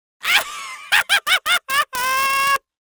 Wild Laughs Male 01
Wild Laughs Male 01.wav